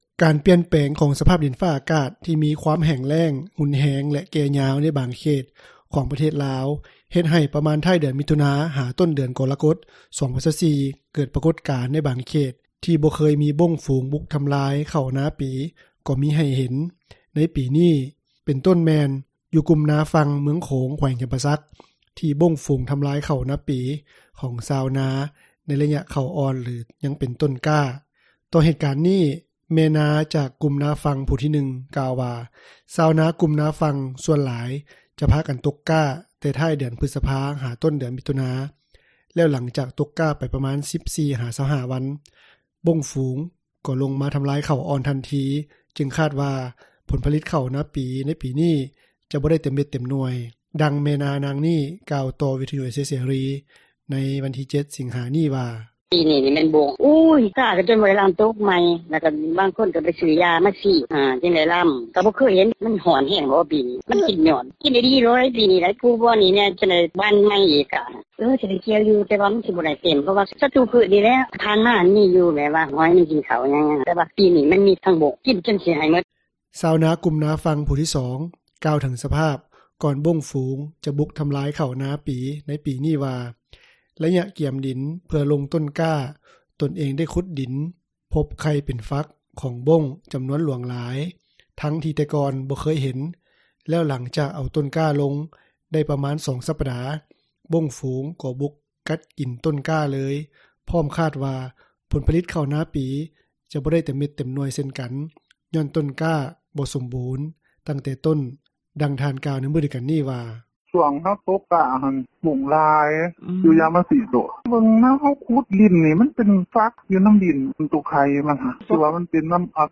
ດັ່ງແມ່ນາ ນາງນີ້ ກ່າວຕໍ່ວິທຍຸເອເຊັຽເສຣີ ໃນວັນທີ 7 ສິງຫາ ນີ້ວ່າ:
ດັ່ງຍານາງກ່າວວ່າ: